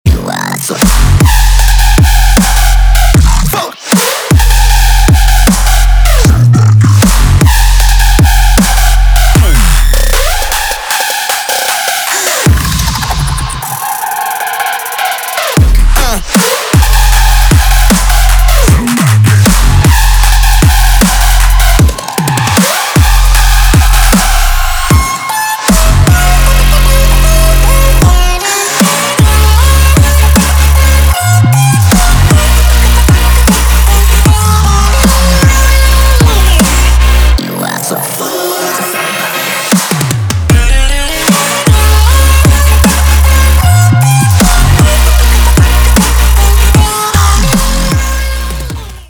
громкие
жесткие
мощные
Electronic
Trap
club
Bass
festival trap